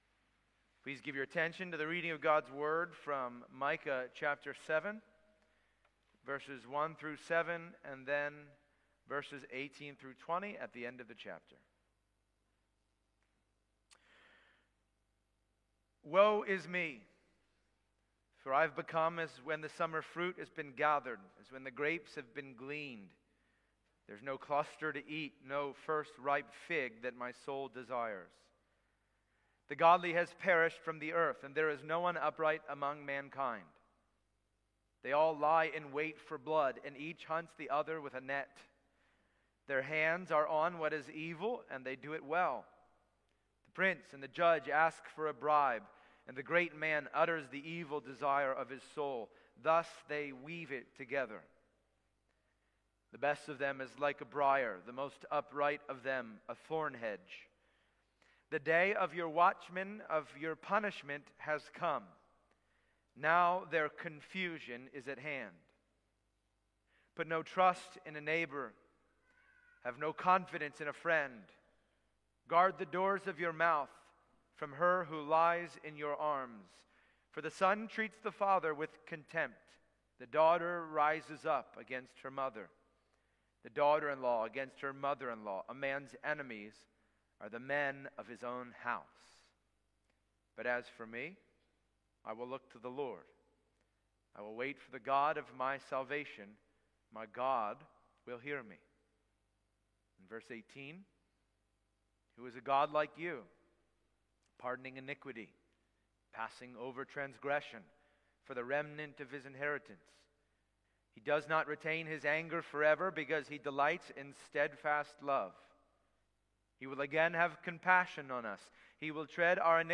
Passage: Micah 7:1-7, 18-20 Service Type: Sunday Morning